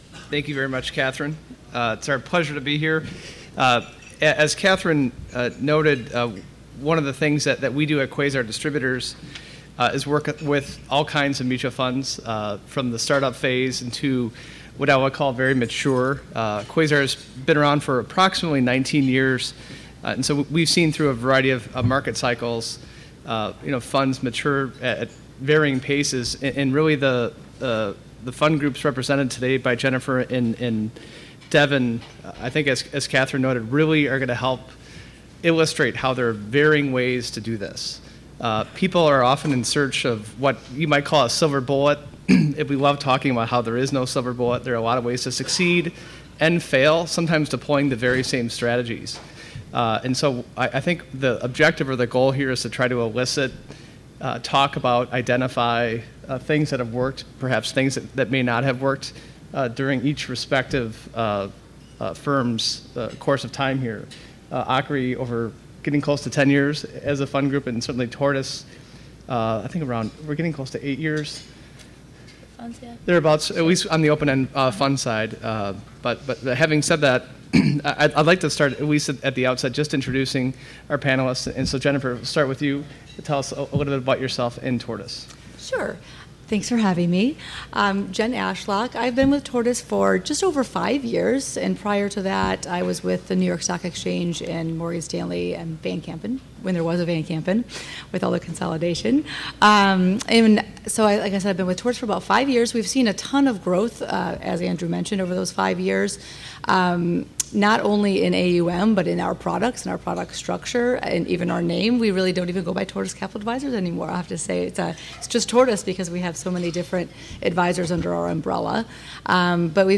This transcript is from the Mutual Fund Case Study panel from the SunStar Strategic 2019 Conference.